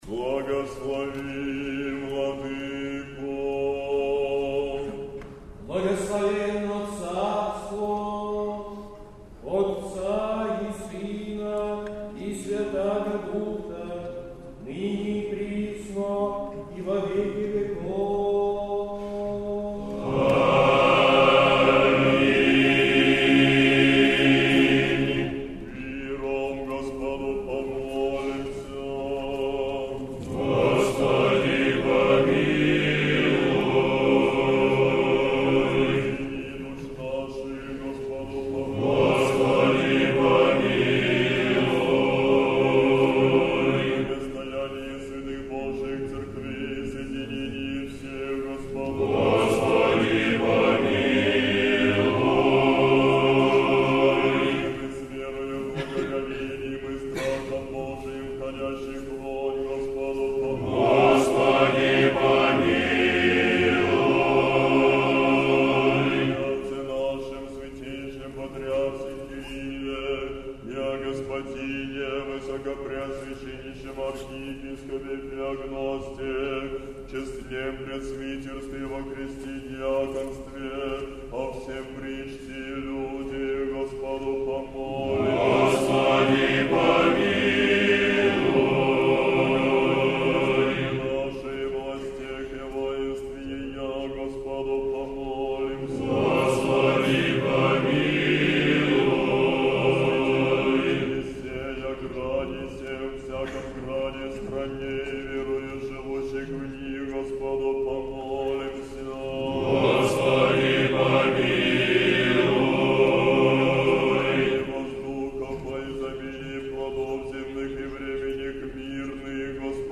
3 июня 2011 года в московском ставропигиальном Сретенском монастыре торжественно отметили праздник Владимирской иконы Божией Матери.
Божественная литургия 21 мая / 3 июня 2011 г. Пятница 6-й седмицы по Пасхе. Сретенский монастырь. Хор Сретенского монастыря.